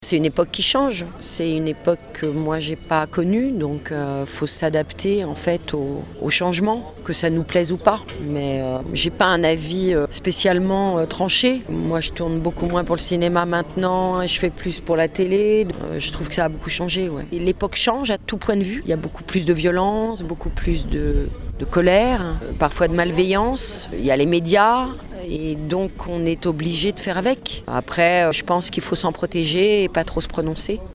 Après Laurent Gerra l’an dernier, c’est l’actrice Mathilde Seigner qui est la marraine du festival cette année. Nous l’avons rencontré. Elle nous livre son regard sur le cinéma français.